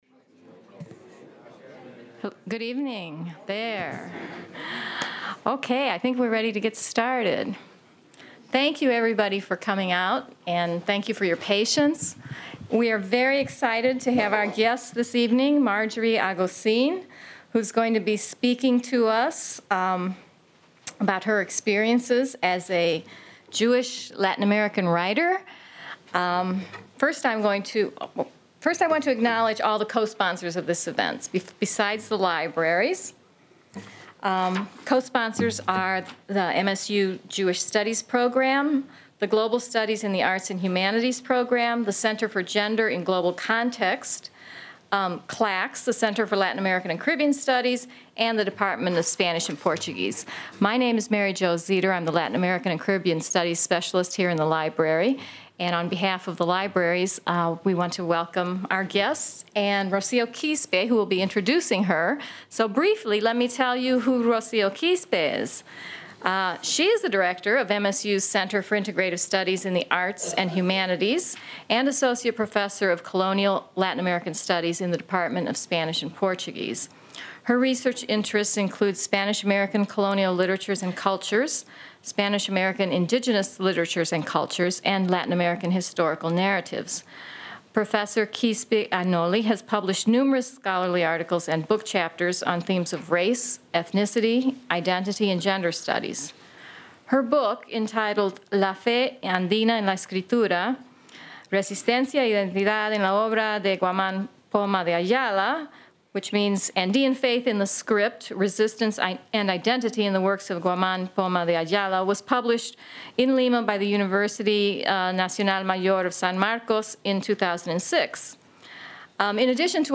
Chilean essayist, novelist, professor, and poet Marjorie Agosín speaks at the Michigan State University Main Library about her life, family and experiences in Chile and the United States. Professor Agosin discusses how being a Jewish Latin American has influenced her writing and relates the history of Jewish dislocation and the migration of her own family throughout the world. She also explains her development as a writer and discusses why she uses memoirs, poetry and novels to tell the story of Jews in Latin America living among German Nazi expatriates. Agosín reads several of her poems that illustrate her experiences.